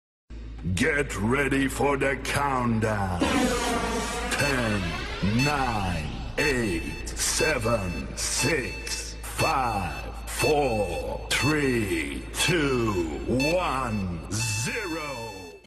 ghbot - Discord version of greenhambot -- currently just plays meme sfx in voice channels + static text commands
countdown.mp3